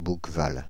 Bouqueval (French pronunciation: [bukval]
Fr-Bouqueval.ogg.mp3